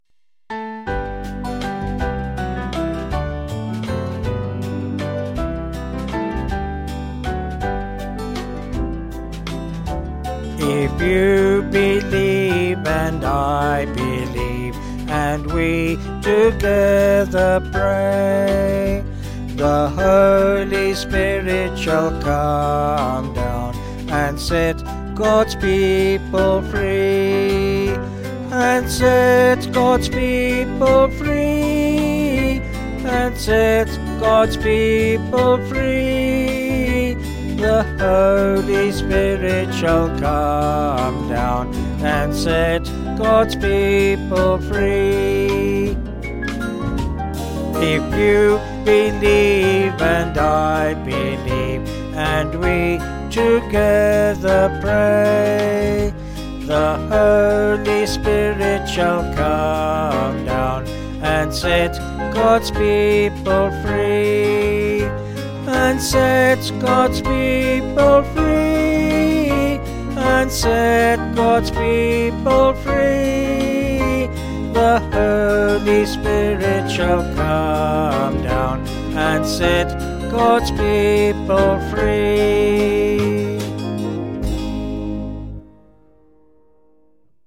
Vocals and Band   701.4kb